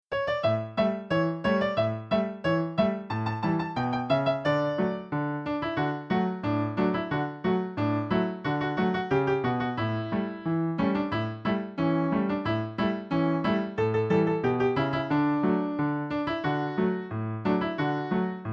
Traditional Song Lyrics and Sound Clip